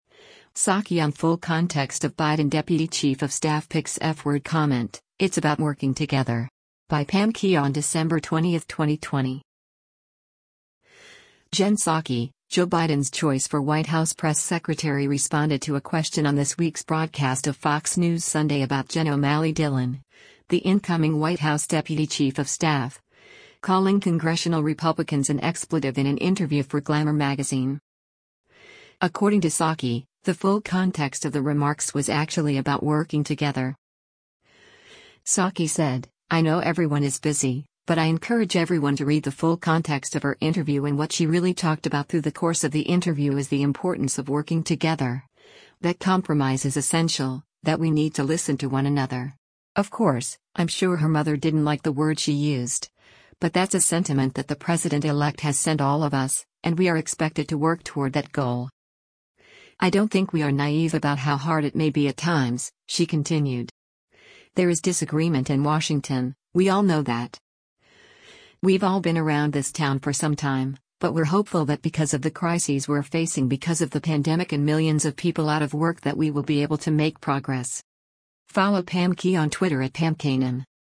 Jen Psaki, Joe Biden’s choice for White House press secretary responded to a question on this week’s broadcast of “Fox News Sunday” about Jen O’Malley Dillon, the incoming White House deputy chief of staff, calling congressional Republicans an expletive in an interview for Glamour Magazine.